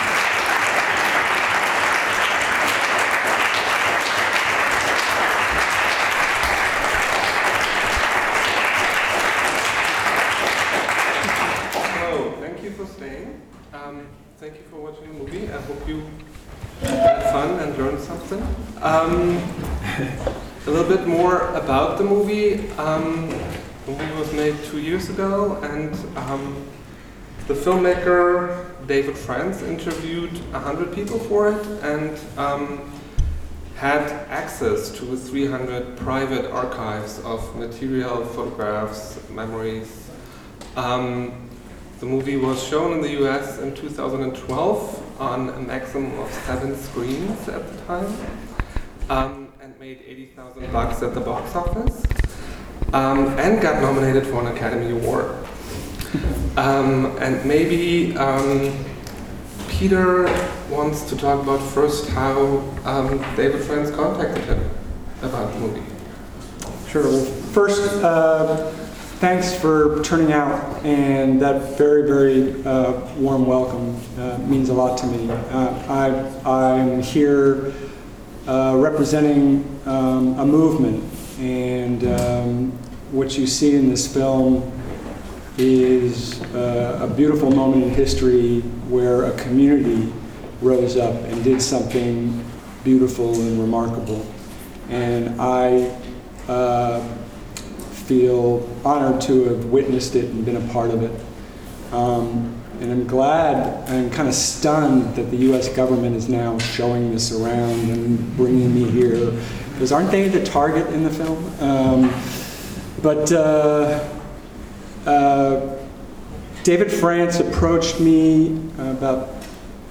ACT UP – Making History . Film und Talk (in englischer Sprache). Am 6. Mai 2015, 18 bis 21 Uhr, an der Humboldt-Universität zu Berlin in der Dorotheenstr. 24, Raum 1.101. Gezeigt wird der Oscar-nominierte Dokumentarfilm „How to Survive a Plague“ (USA 2012).
Fotos der Veranstaltung Audioaufzeichnung der Veranstaltung